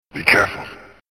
You hear Batman gruffly say,
As an aside, it obviously doesn't come across at all in still pictures, but every level is peppered with voice clips direct from the film.